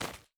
Jump_2.wav